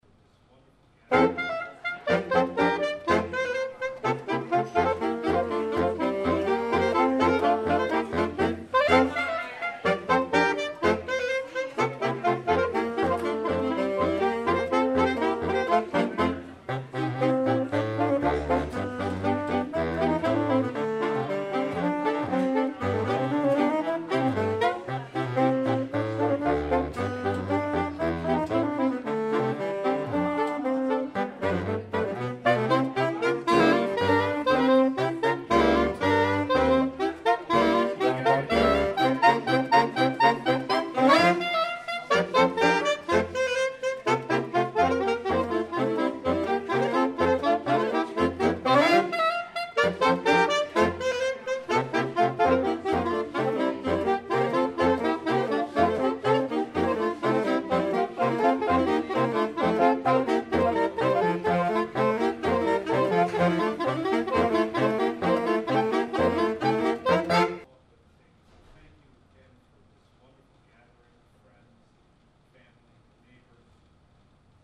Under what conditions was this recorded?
And here’s a classic favorite from a holiday party with saxophone music.